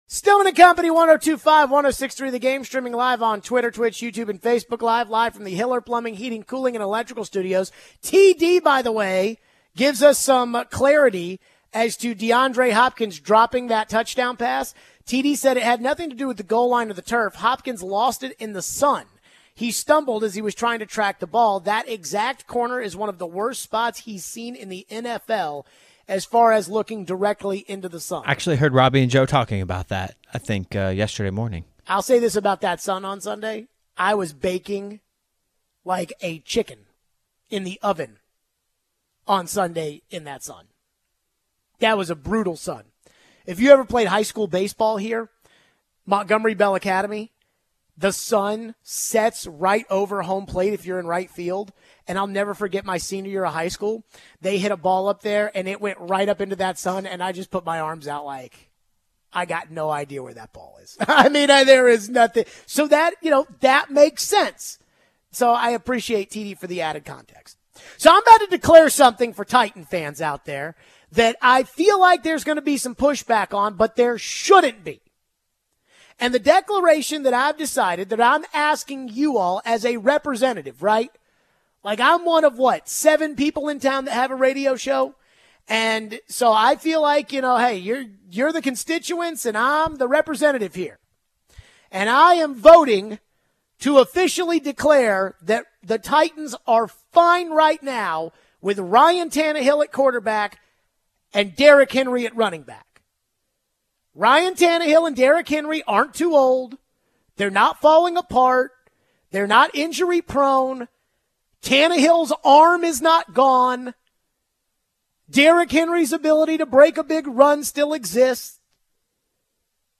Tannehill and Henry are playing just fine for the Titans as long as the offense around them is competent. We take your phones. How big of a deal is it that Henry passed Earl Campbell in rushing yards? What is going on with the Steelers?